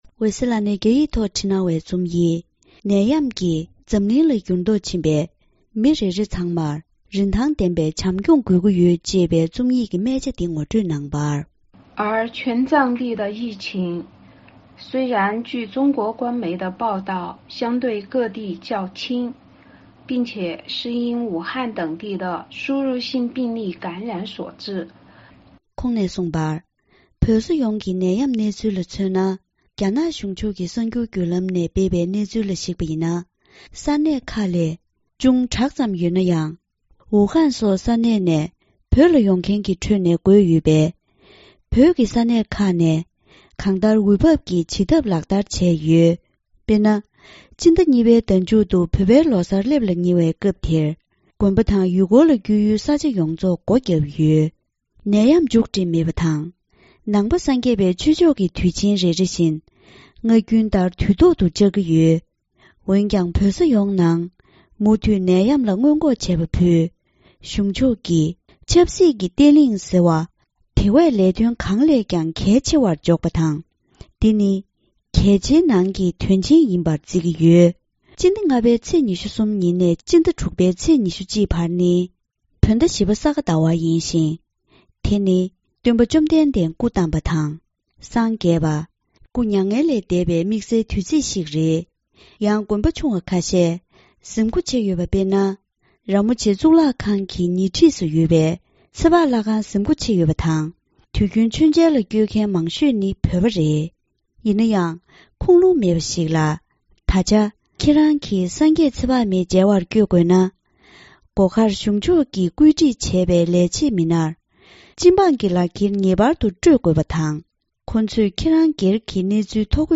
ཕབ་བསྒྱུར་སྙན་སྒྲོན་གནང་གི་རེད།